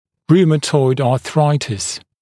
[ˈruːmətɔɪd ɑː’θraɪtɪs][ˈру:мэтойд а:’срайтис]ревматоидный артрит